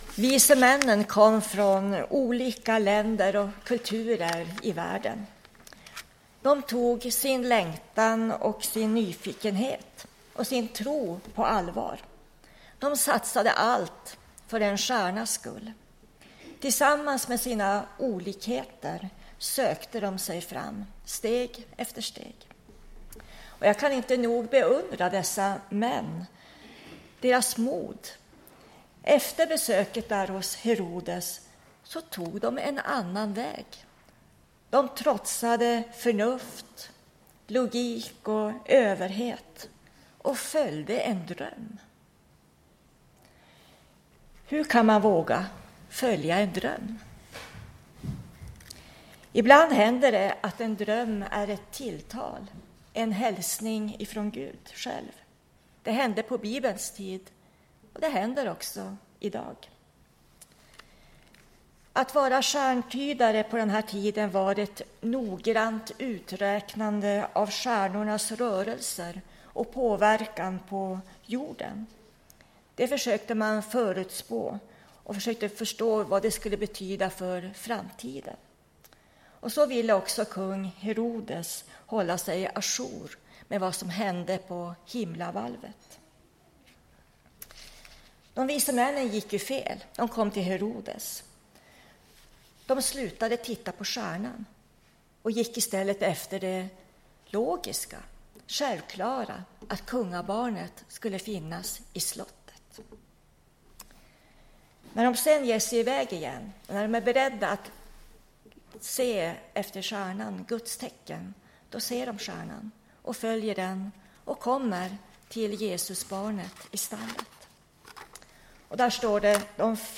Tidigare kunde du lyssna på en predikan från någon av helgens gudstjänster inom Svenska kyrkan i Boden på Bodens närradio 98,2 MHz.